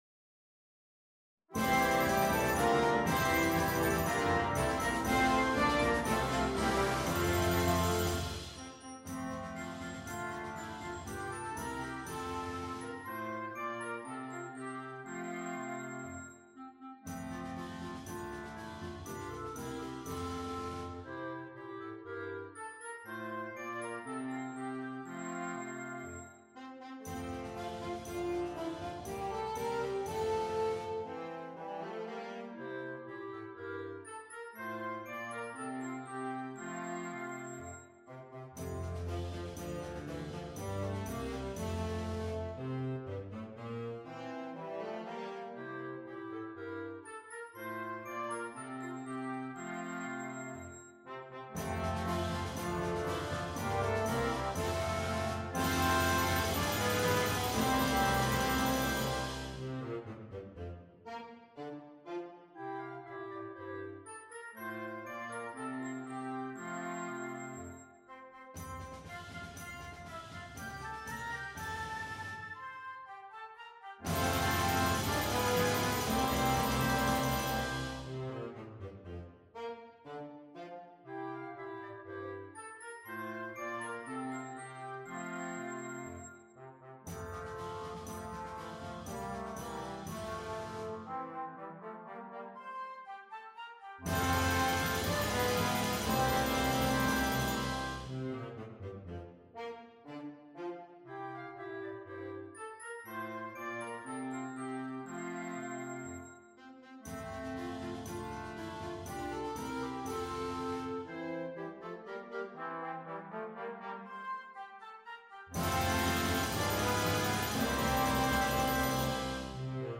This cheerful musical gift